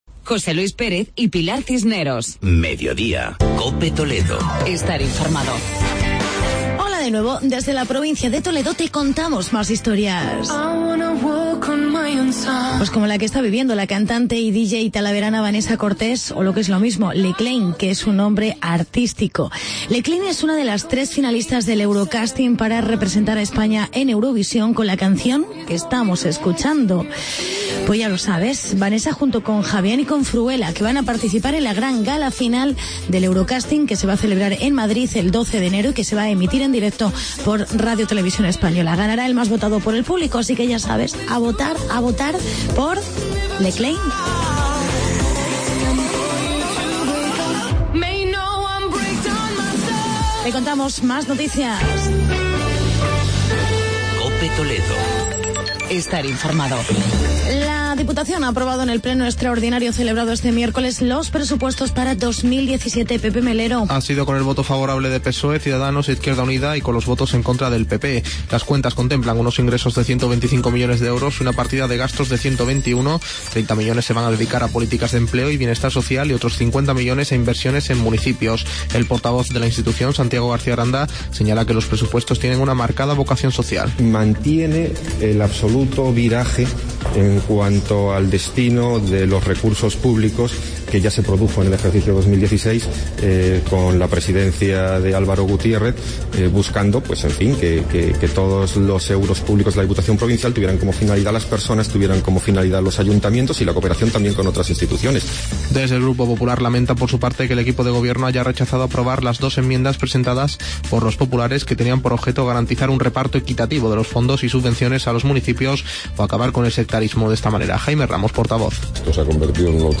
Actualidad y entrevista con el alcalde de Talavera de la Reina, Jaime Ramos.